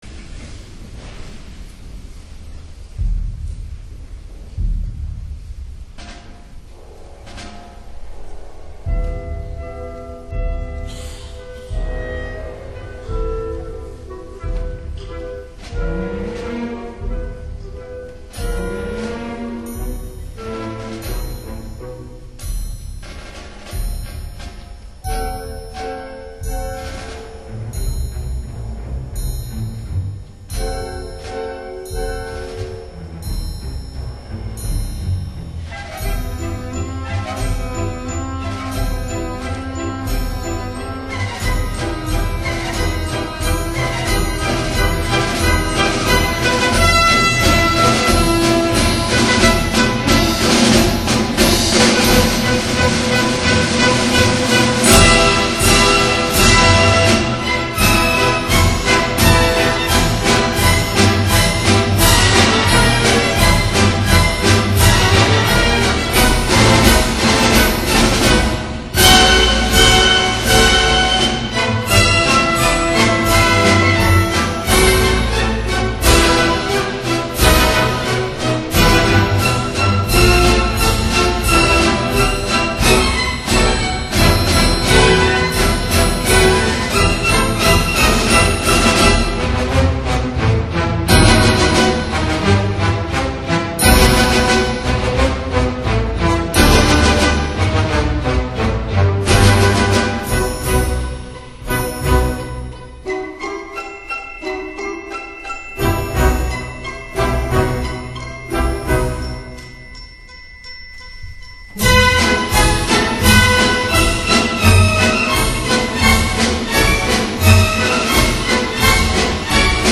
维也纳金色大厅